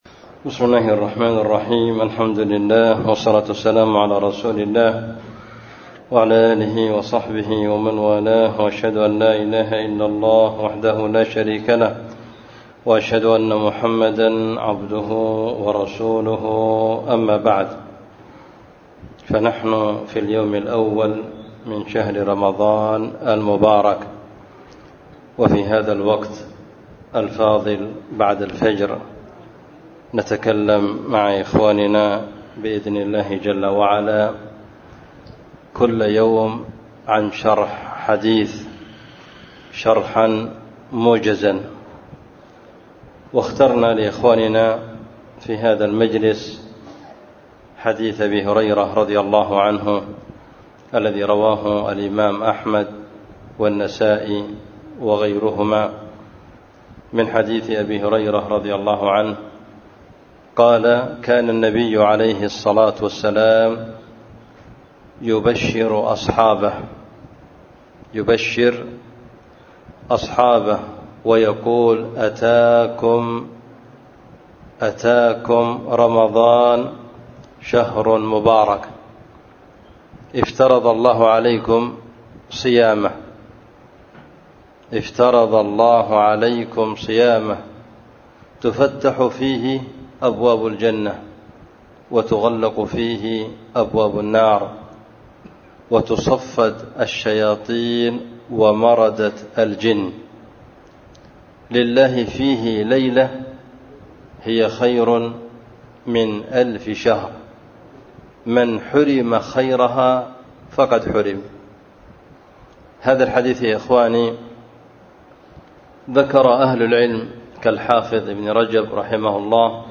بدار الحديث في مسجد النصيحة بالحديدة